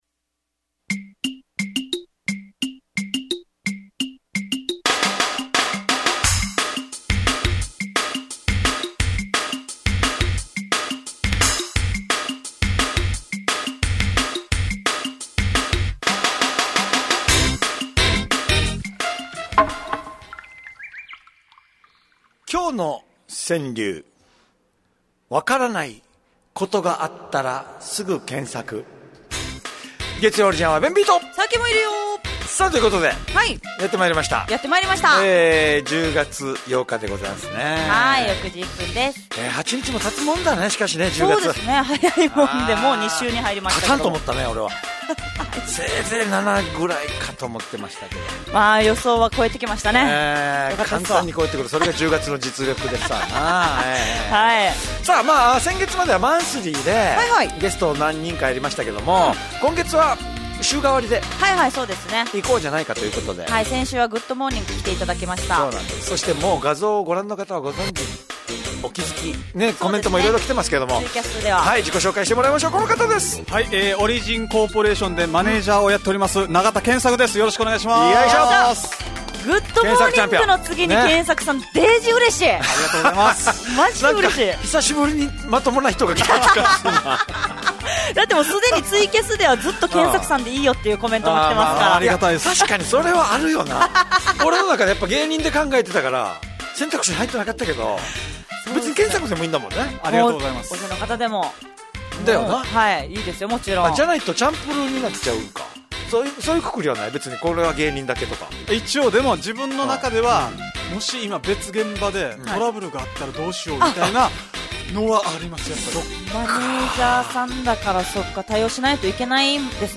fm那覇がお届けする沖縄のお笑い集団・オリジンメンバー出演のバラエティ番組の